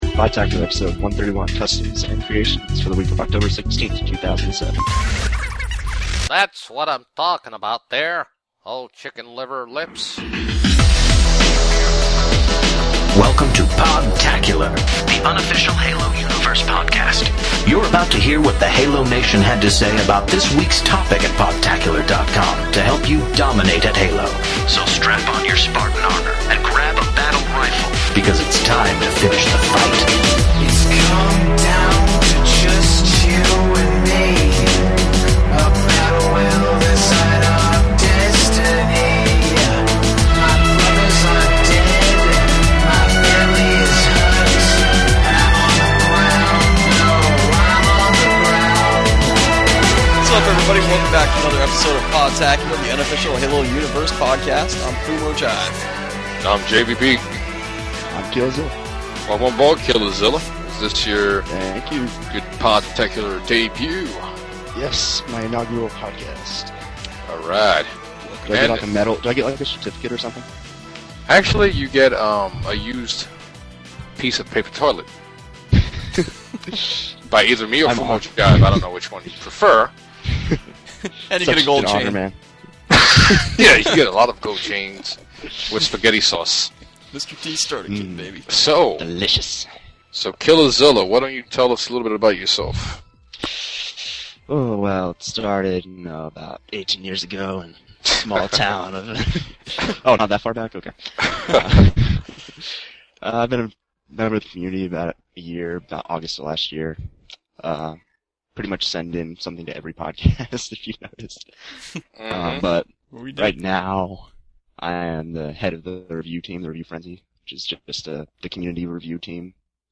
Check it out in the outtakes!